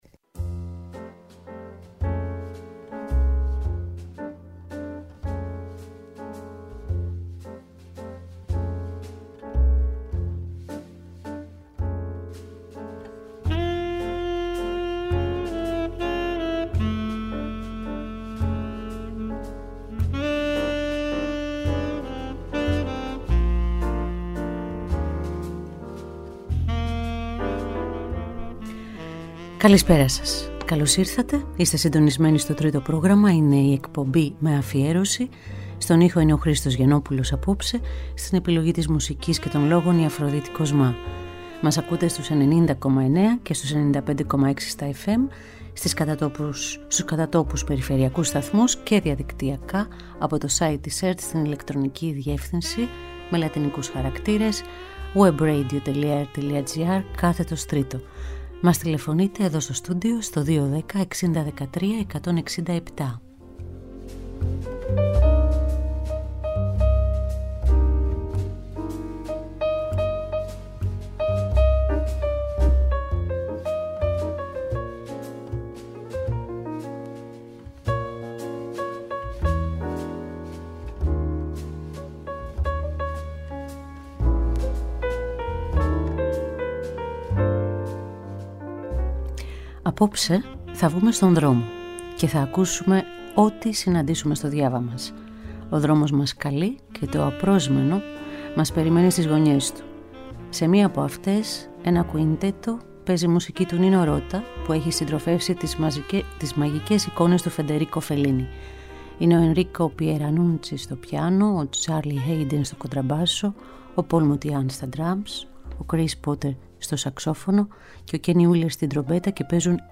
Κάθε Πέμπτη, ζωντανά από το στούντιο του Τρίτου Προγράμματος 90,9 & 95,6